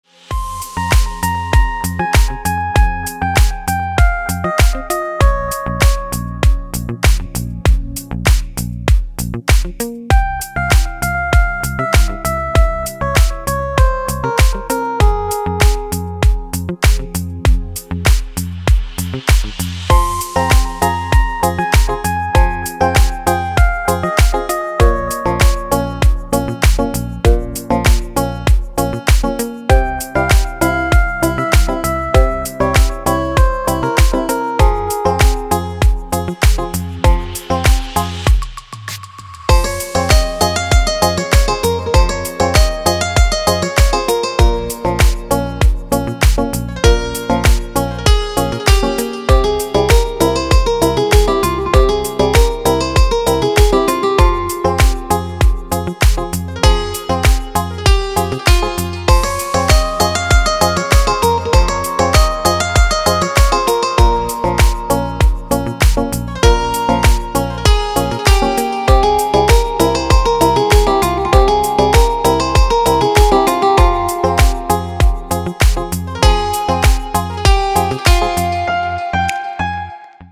Красивый мотив для звонка